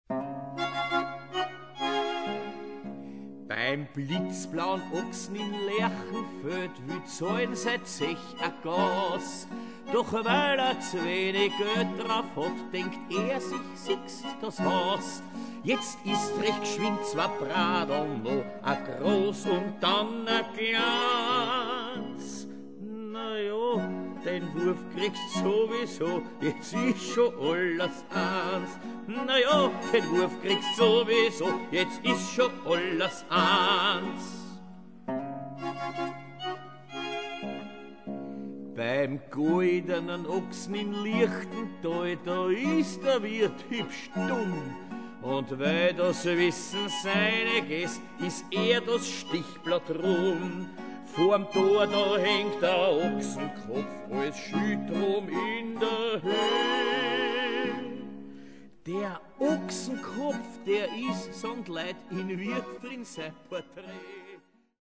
Kontragitarre
Knopfharmonika
G-Klarinette